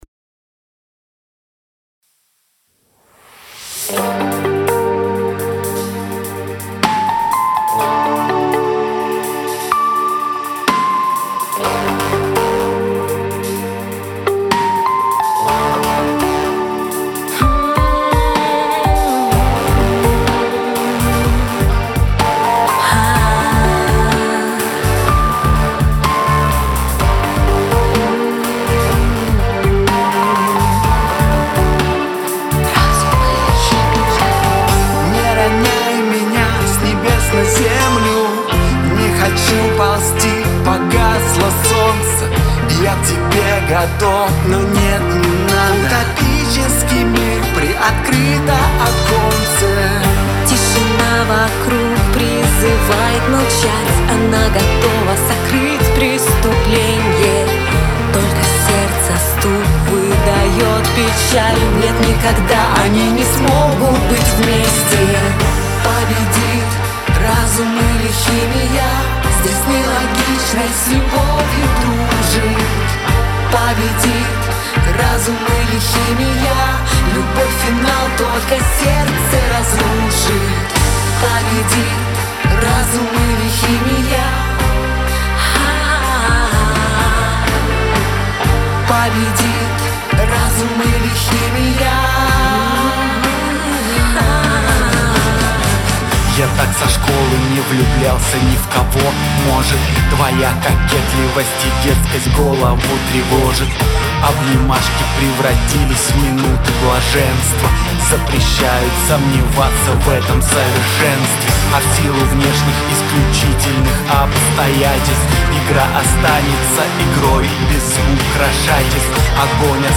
Уважаемые участники форума, выскажите, плиз, критику и мысли в каком направлении поработать, чтобы улучшить сведение, а может жаже подскажете как;) Мне не хватает плотности и полноты в нижней середине. И кажется, что ядовито звучит в верхней середине. + все плохо с пространством.
Не всё так плохо для начинающего Со стерео-расширялками перебор, поэтому звучит широко и плоско.
Яд в районе 6кгц обычно давит на уши.